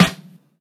SDFFA_SNR.wav